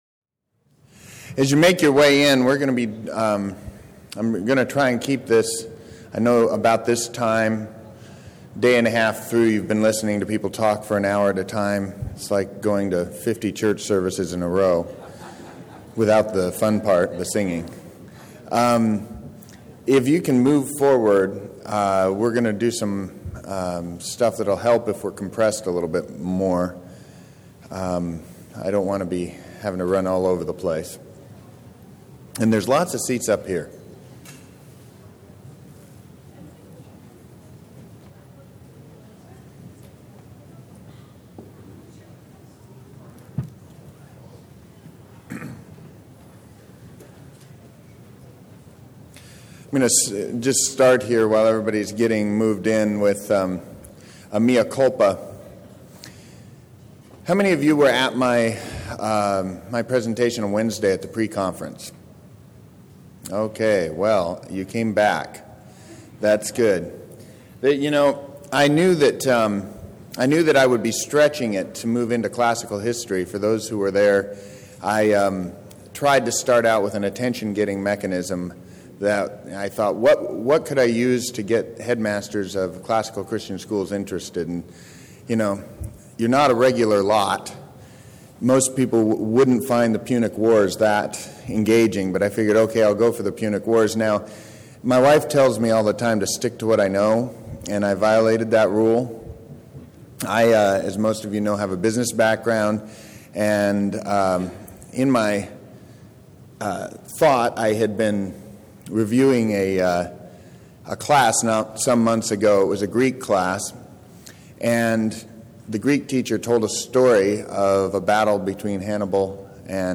2012 Workshop Talk | 1:02:27 | Leadership & Strategic, Marketing & Growth